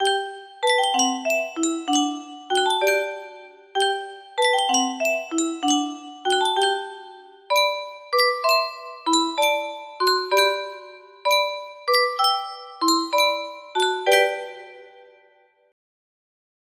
Clone of Yunsheng Spieluhr - C.M. Haug O Maria 1450 music box melody